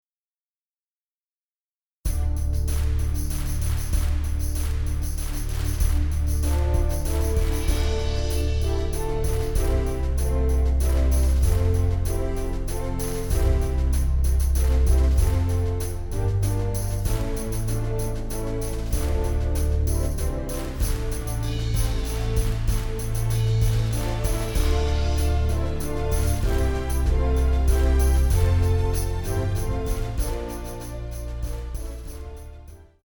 Genre film / musical
• Instrument: Fluit